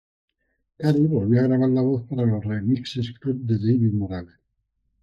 Read more to record to engrave Frequency C1 Hyphenated as gra‧bar Pronounced as (IPA) /ɡɾaˈbaɾ/ Etymology Borrowed from French graver In summary Borrowed from French graver.